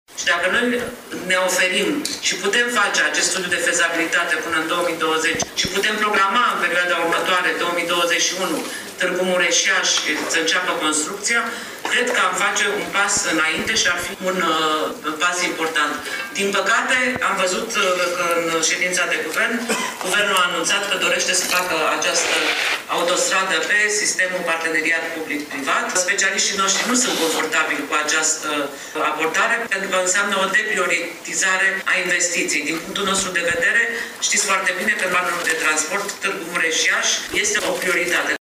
La prima conferință de presă a săptămânii europene a regiunilor și orașelor, care se desfăşoară la Bruxelles, comsiarul european a precizat că momentan nu a primit solicitări pentru finanțarea studiului de fezabilitate a obiectivului de infrastructură: